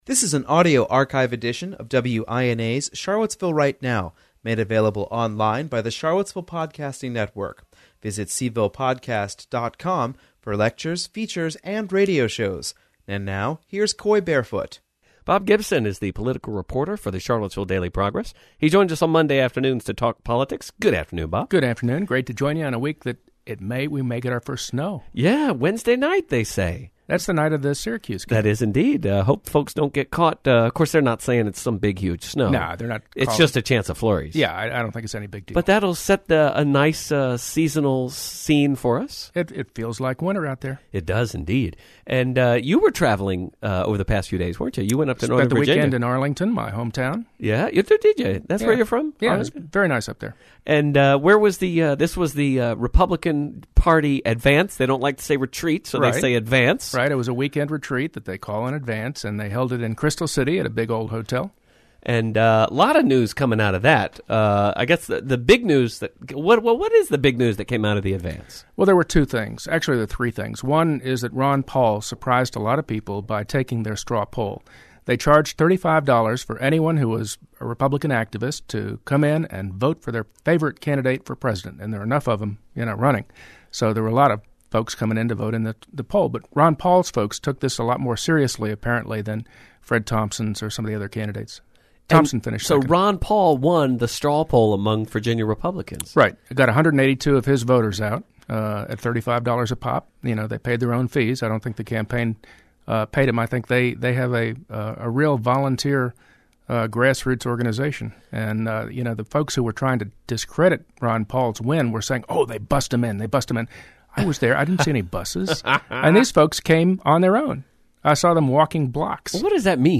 a discussion of Virginia politics on WINA’s Charlottesville–Right Now. This week, Ron Paul wins the Republican straw poll at their advance this past week.